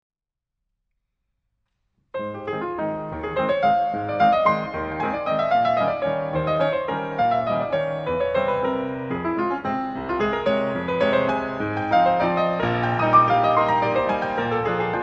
Classical, Piano